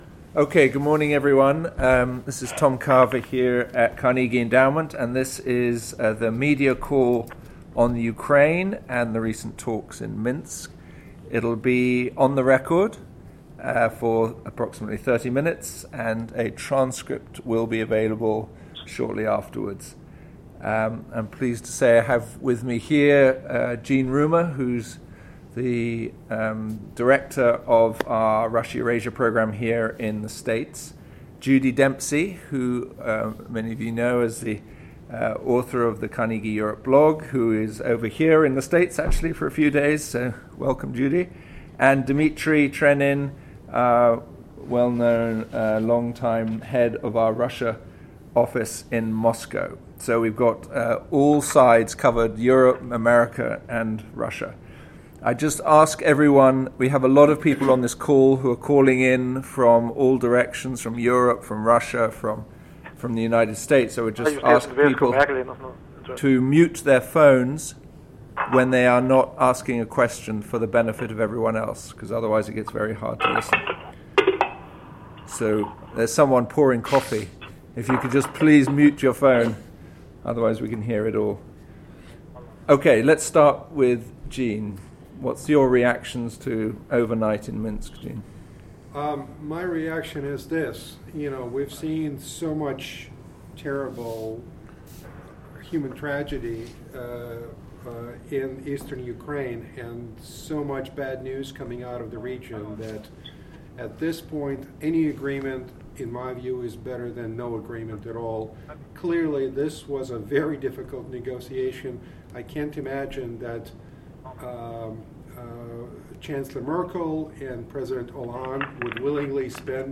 Media Call: Ukraine Conflict
Ukraine media call 2-12-edited.mp3